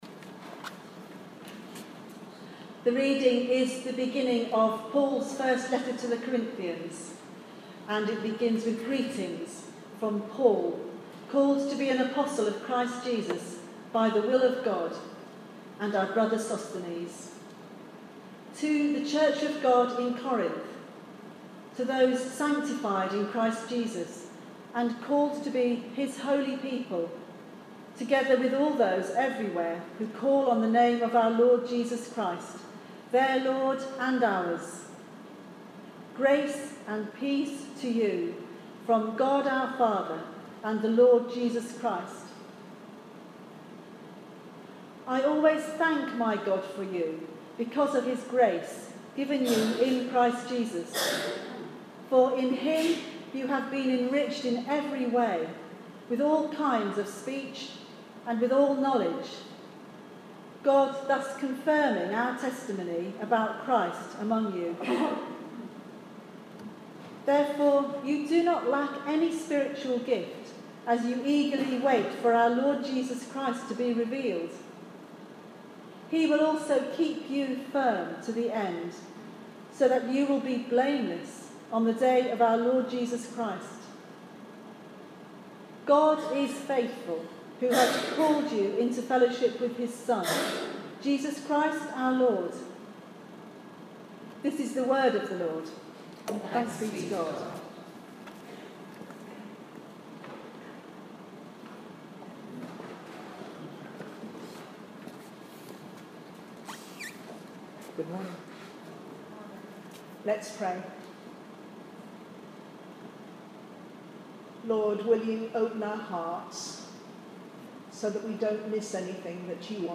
Sermon 15 January 2017
Listen to the Reading 1 Corinthians 1:1-9, and Sermon: